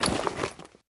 Шорох защитной экипировки при надевании